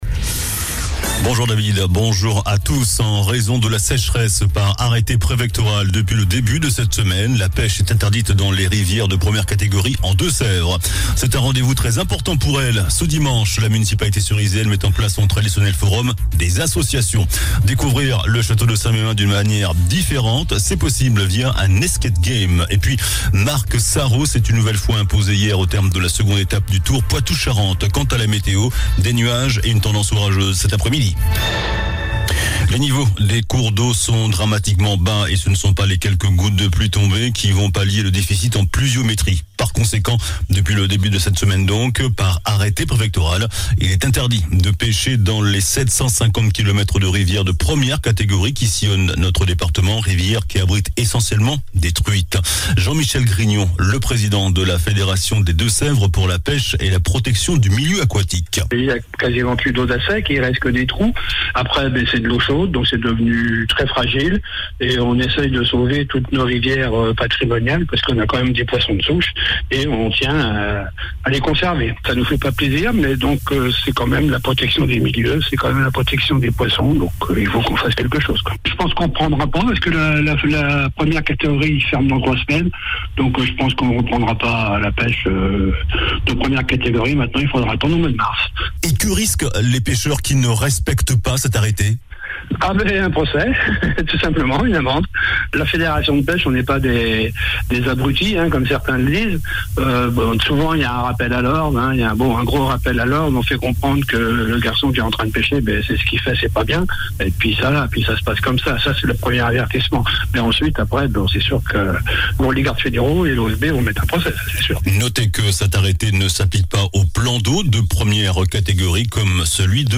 JOURNAL DU JEUDI 25 AOÛT